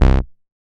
MoogAgressPulseA.WAV